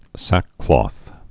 (săkklôth, -klŏth)